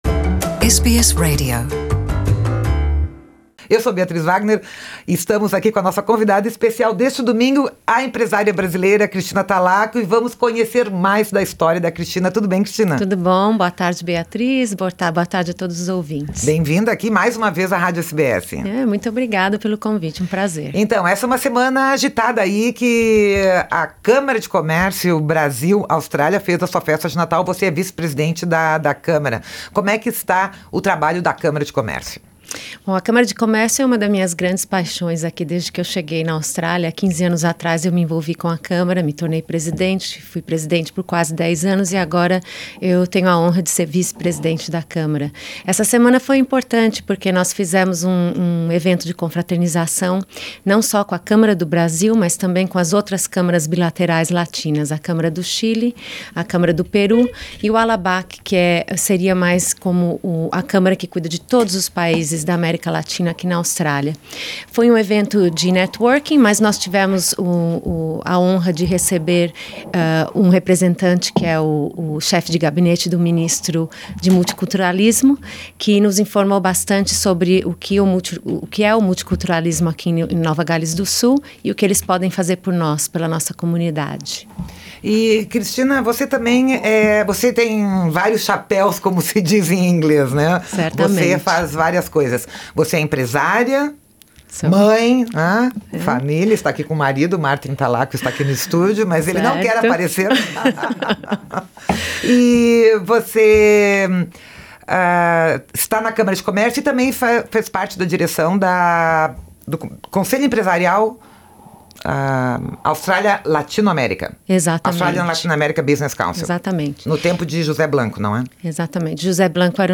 Confira a entrevista completa da empresária nos estúdios da SBS em português.